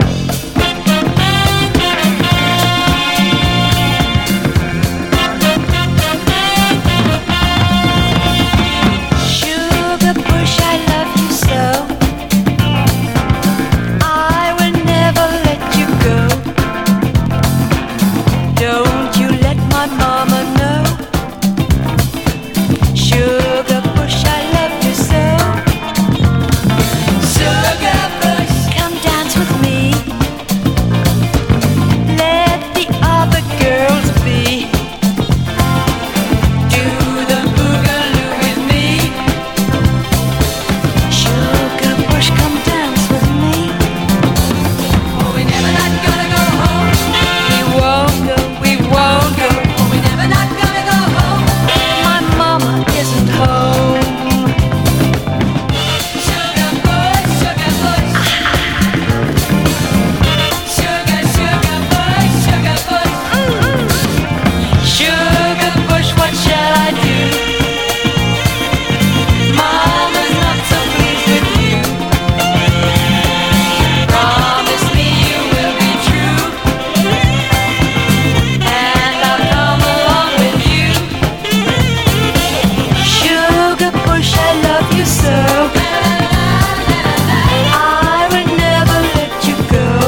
SOUL / SOUL / 60'S / RHYTHM & BLUES / MOD / POPCORN
ソウルフルな擦れ声に心底惚れ惚れします。